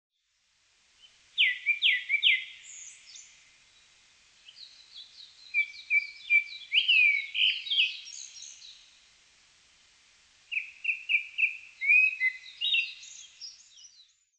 クロツグミ　Turdus cardisツグミ科
日光市稲荷川中流　alt=730m  HiFi --------------
Windows Media Audio FILE MPEG Audio Layer3 FILE  Rec.: SONY TC-D5M
Mic.: 自作  Binaural Souce
他の自然音：　 メジロ